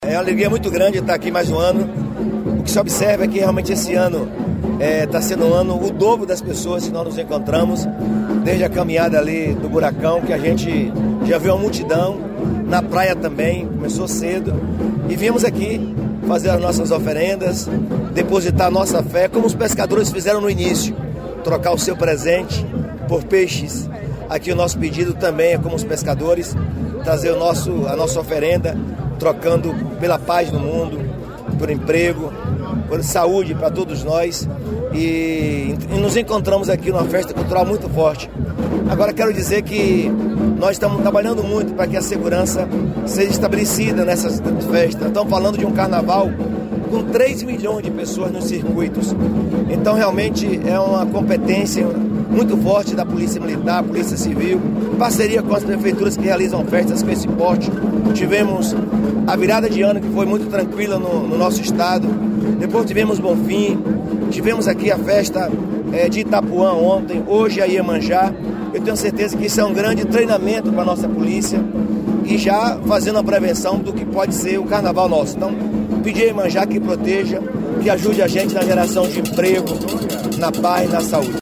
O governador Jerônimo Rodrigues, pedi paz a Iemanjá
Acompanhado do vice-governador, Geraldo Júnior, e de secretários de Estado, o governador Jerônimo Rodrigues participa nesta sexta-feira (02) da festa de Iemanjá, uma das celebrações mais populares da Bahia.